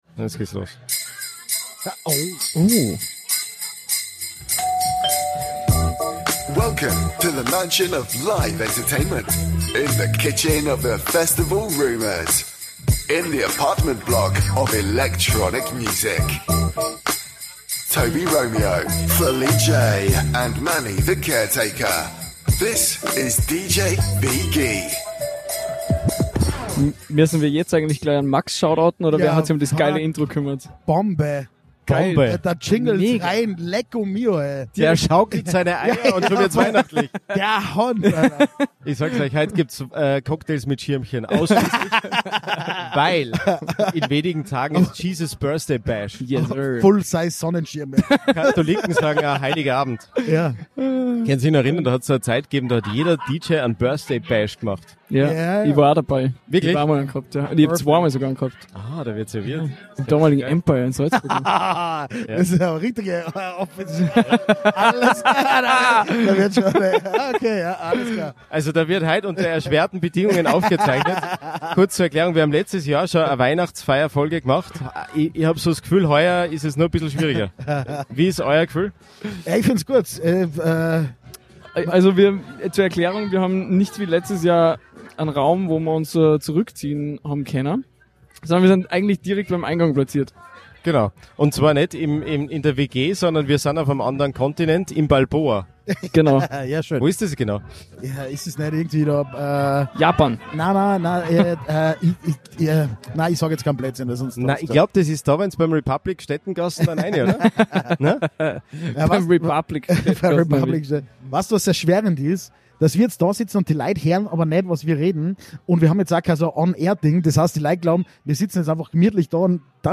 Weihnachtsfeier Special - LIVE aus dem Balboa Club Salzburg Eine Wahrsagerin, Trash or Treausre Glücksrad und sündteurer Tequilla in der Gmundner Keramik Pfeffer Mühle - Das Highlight des Jahres zum Abschluss - Live, direkt und absolut nicht nüchtern.